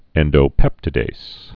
(ĕndō-pĕptĭ-dās, -dāz)